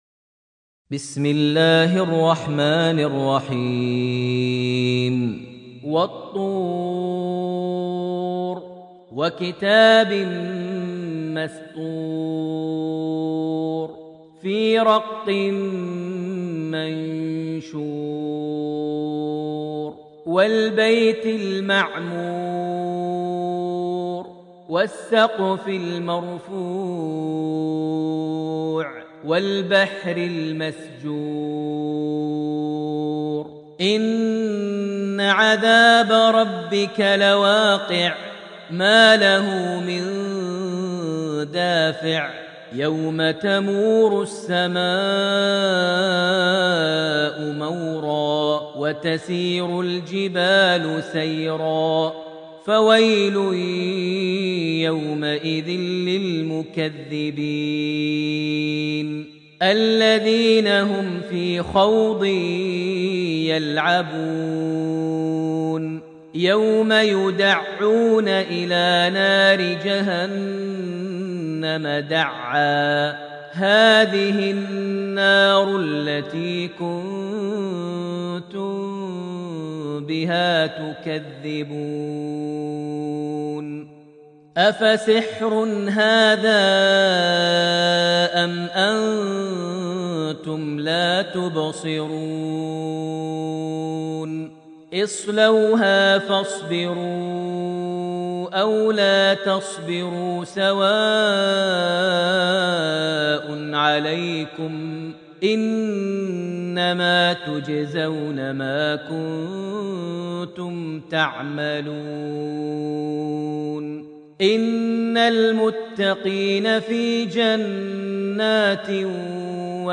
Surat al-Tur > Almushaf > Mushaf - Maher Almuaiqly Recitations